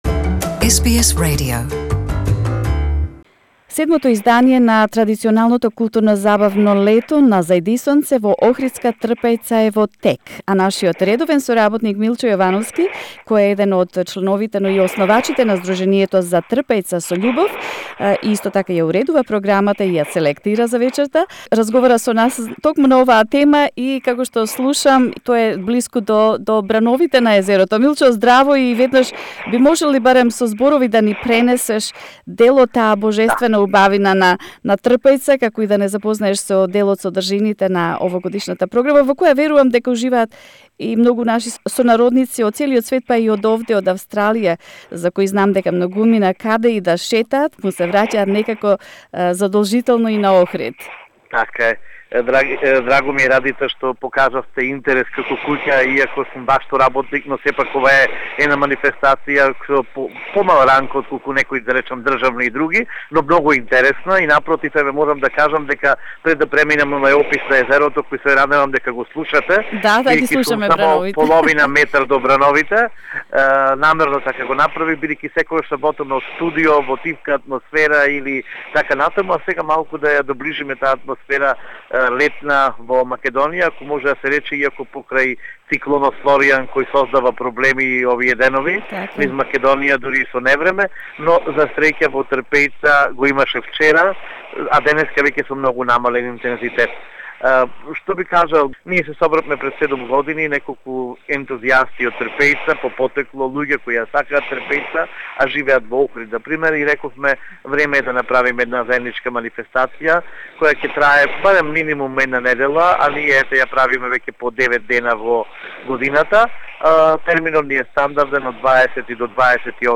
while we hear the 'singing' of the waves of Ohrid Lake. The event is held every year from 20 -28 July under the sky and the performances are free for all visitors of this place with magnificent natural beauty, that many call Macedonian Saint Tropez